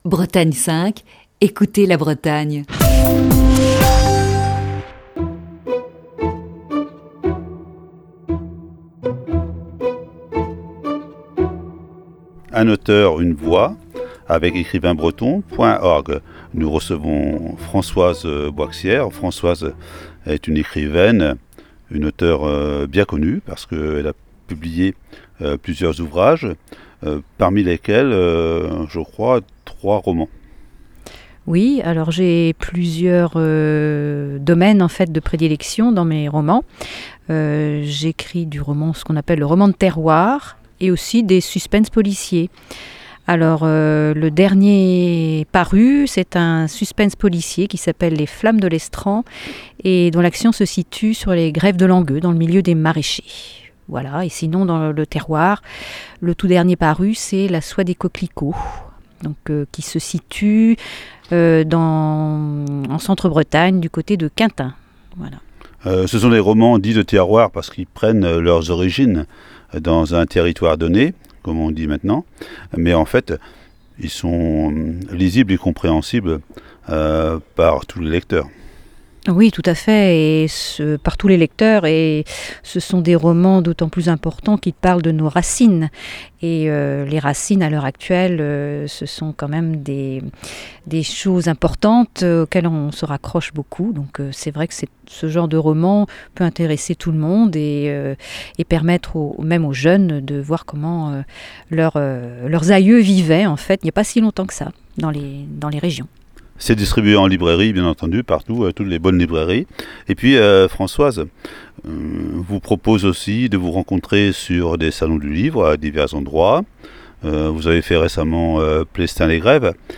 Chronique du 31 janvier 2020.